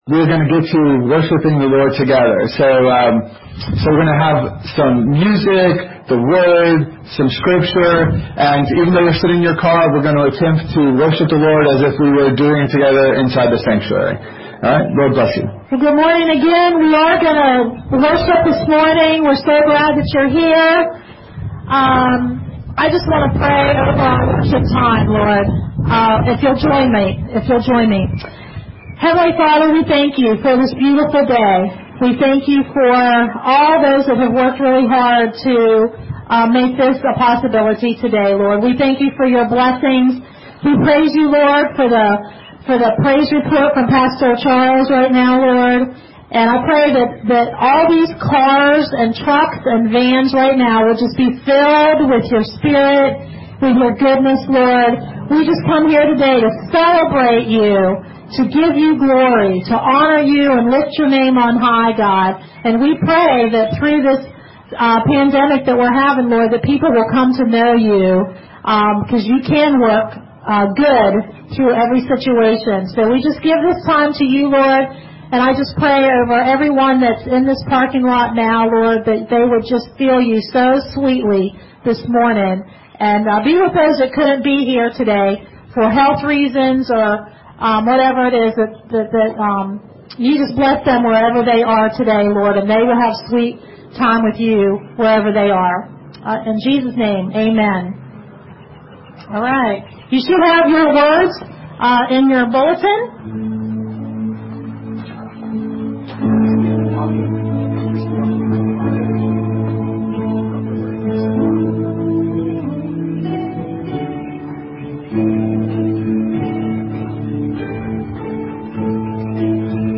Eph 1:19-20 Service Type: Sunday Morning Lots of people are living broken worlds